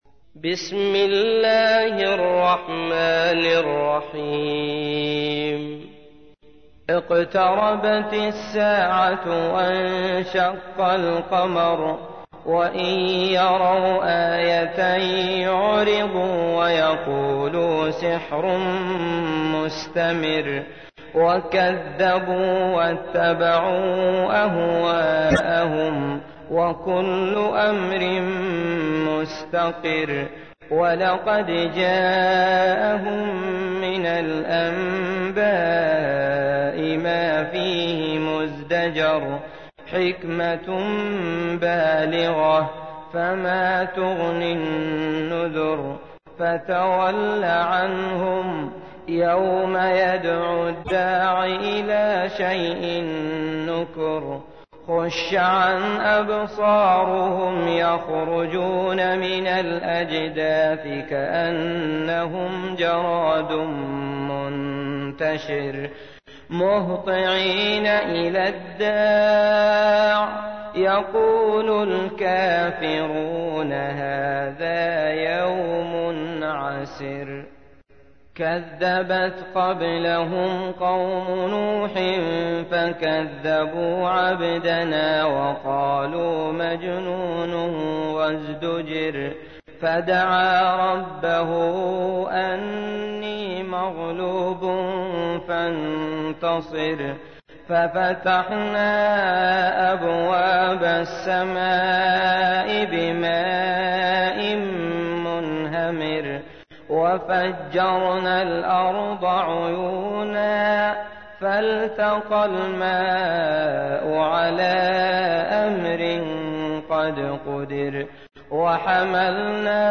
تحميل : 54. سورة القمر / القارئ عبد الله المطرود / القرآن الكريم / موقع يا حسين